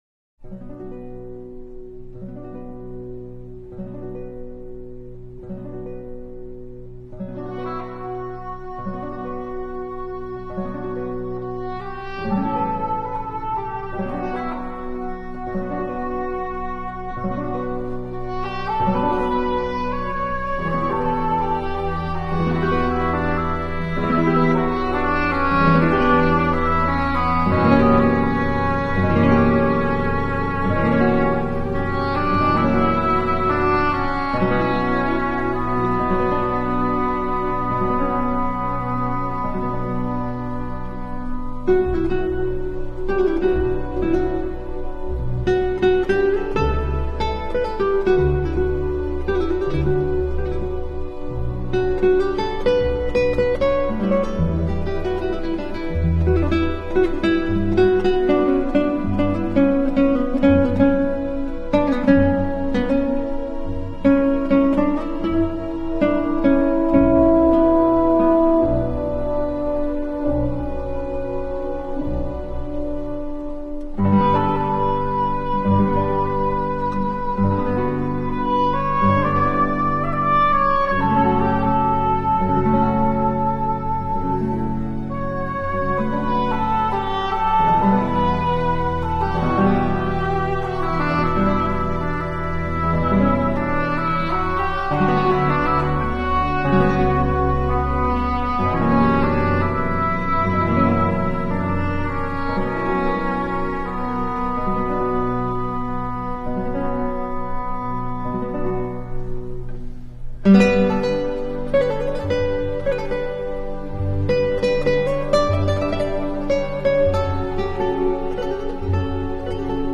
This week we will enjoy a programme of “Sundowner” music. Easy listening for the end of the day.